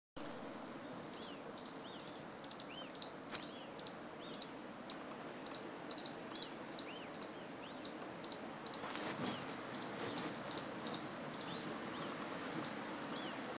White-throated Treerunner (Pygarrhichas albogularis)
Life Stage: Adult
Location or protected area: Parque Nacional Lanín
Condition: Wild
Certainty: Photographed, Recorded vocal
Picolezna-patagonico.mp3